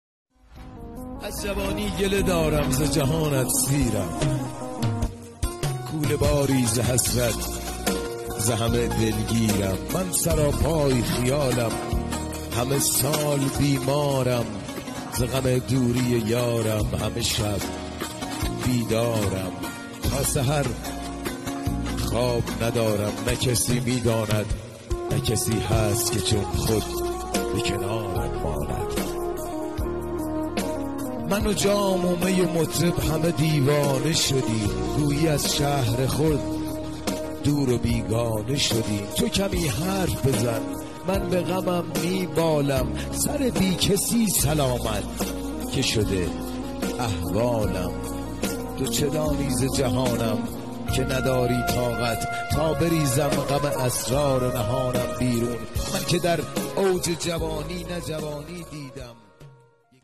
اهنگ دکلمه